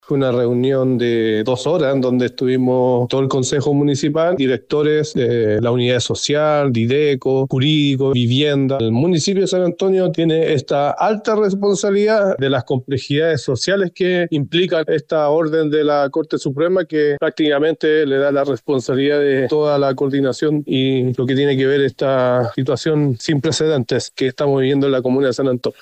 José Manuel Ibarra, concejal independiente de San Antonio, calificó como una “situación sin precedentes” la ejecución del desalojo.